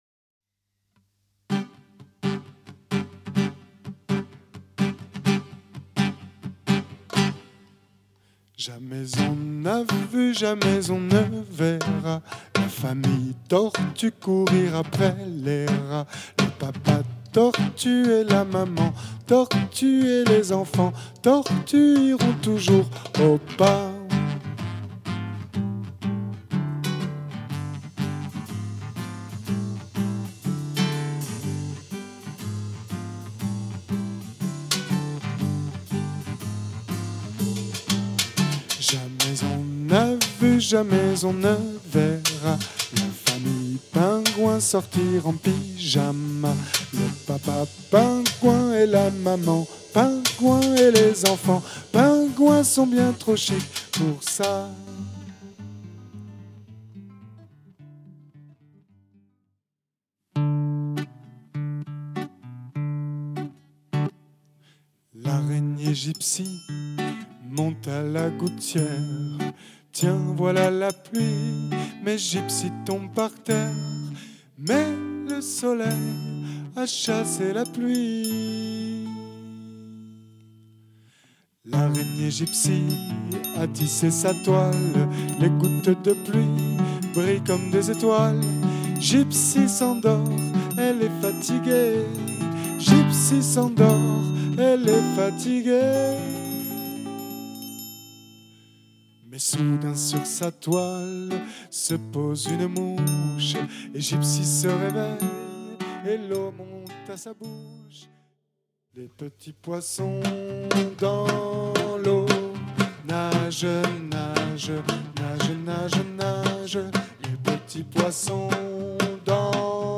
ECOUTEZ NOTRE DEMO